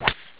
ShotWood.wav